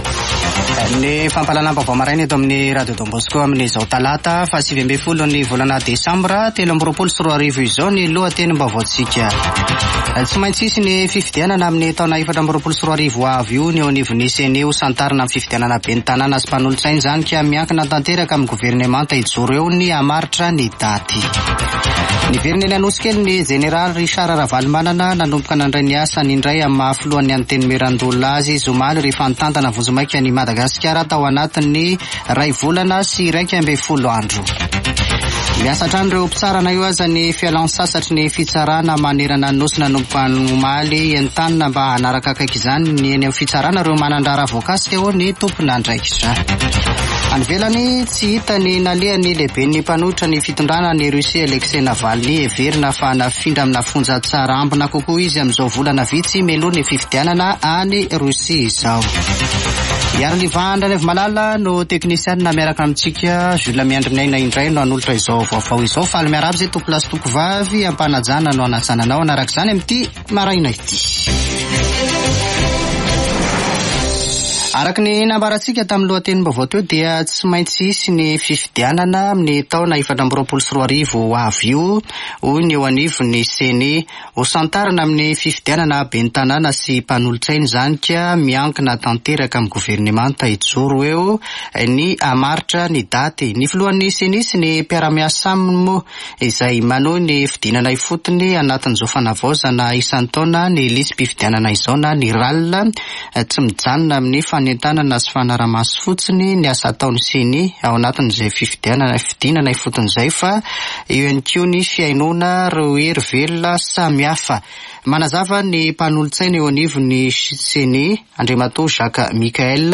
[Vaovao maraina] Talata 19 desambra 2023